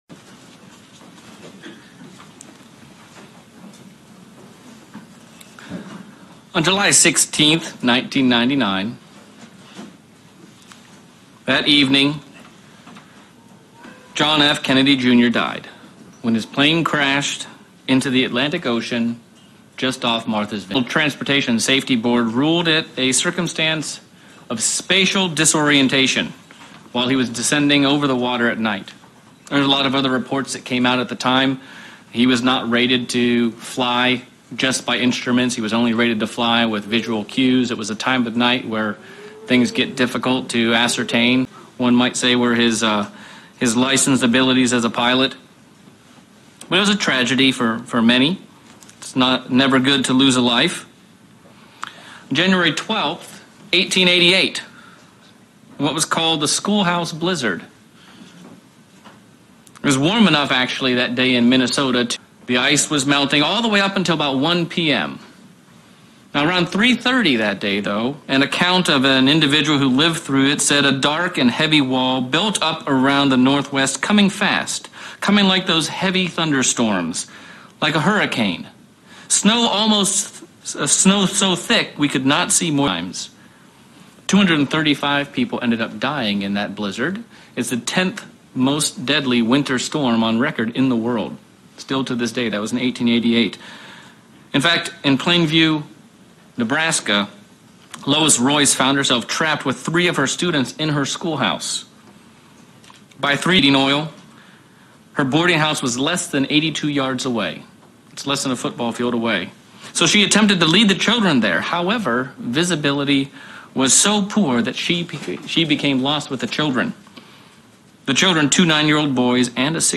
Sermon looking at our spiritual perspective and being on guard against that which can cause us to become spiritually disoriented and thereby lose our spiritual focus